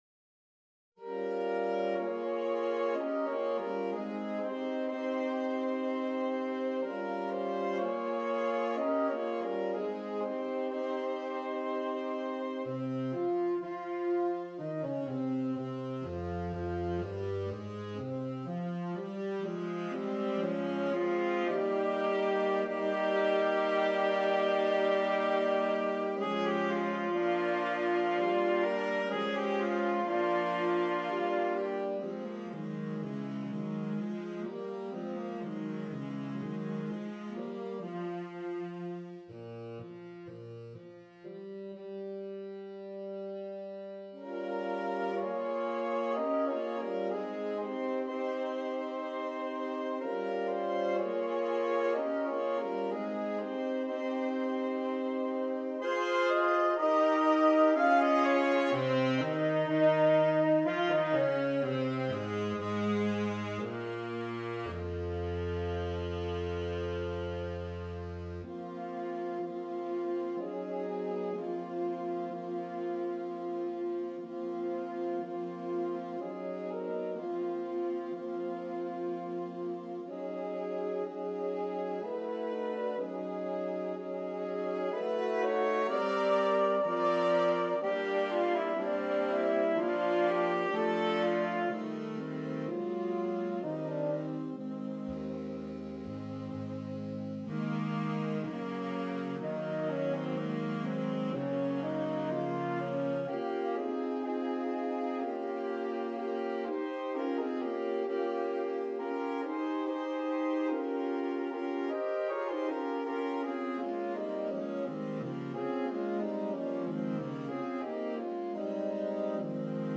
Saxophone Quartet (AATB)